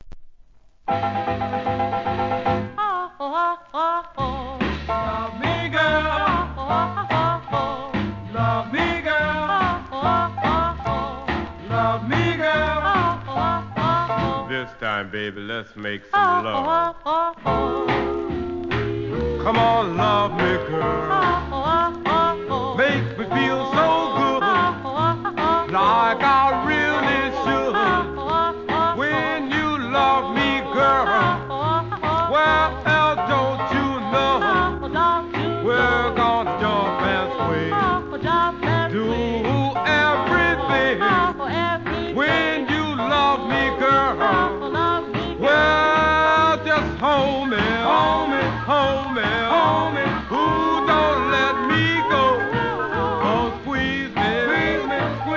店舗 ただいま品切れ中です お気に入りに追加 GREAT DOO-WOPコンピ!!!